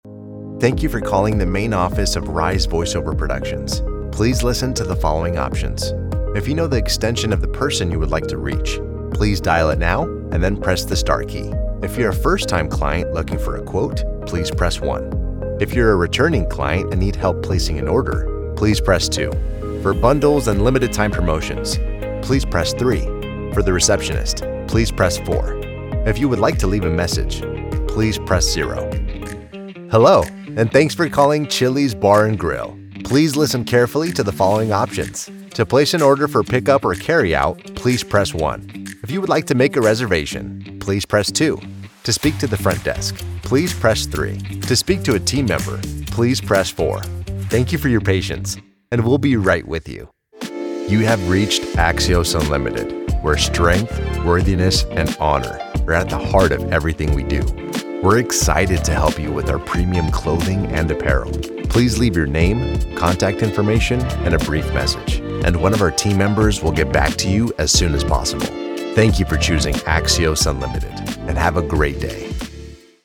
Voice-Over Artist
IVR and Voicemail
Laid Back, Friendly, Professional
IVR-Voicemail-Demo.mp3